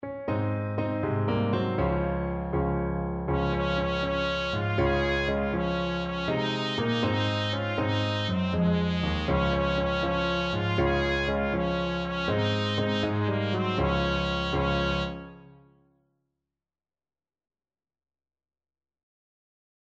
Joyfully .=c.80
6/8 (View more 6/8 Music)